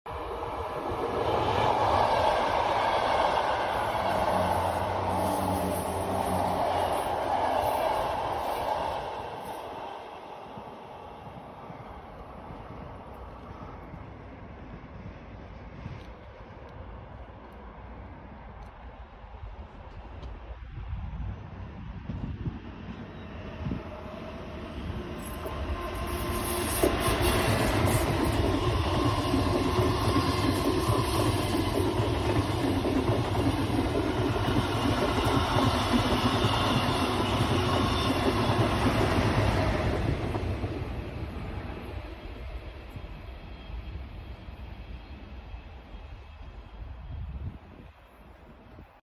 AM80 en passage en gare sound effects free download
AM80 en passage en gare de Schaerbeek